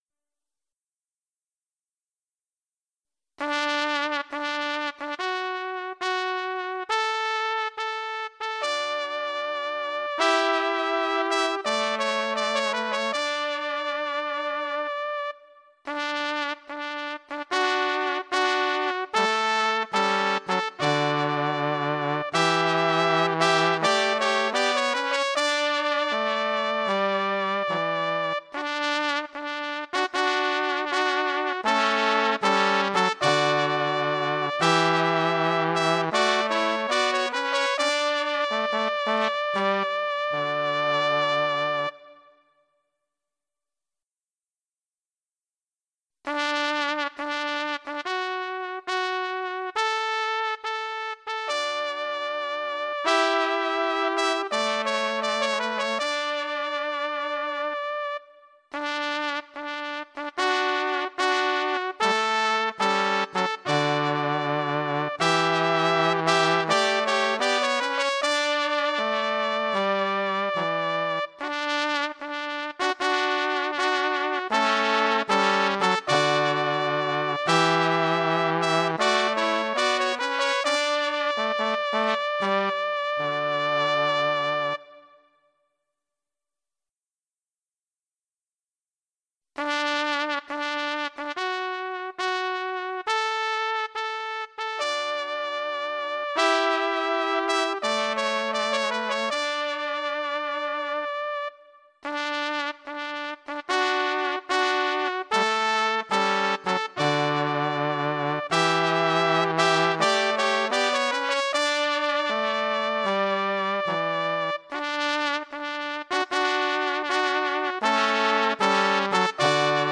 Zamontowane głośniki tubowe są w pełni odporne na działanie warunków atmosferycznych a melodia jest inicjowana automatycznie.